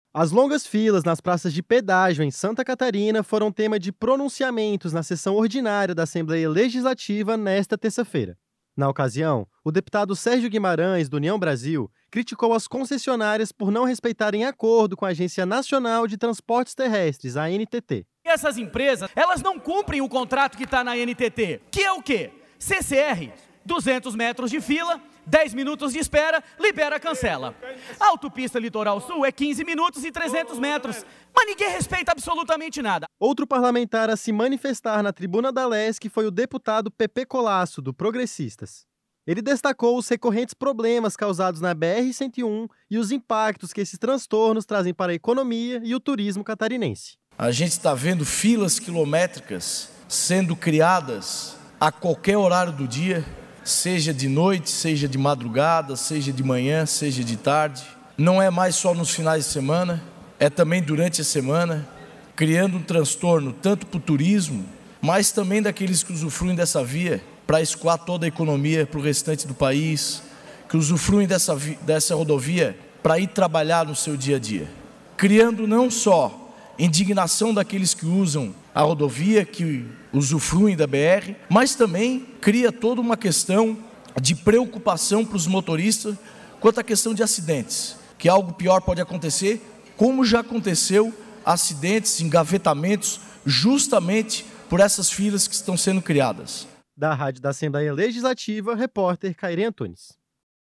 Entrevista com:
- deputado Sérgio Guimarães (União Brasil);
- deputado Pepê Collaço (PP).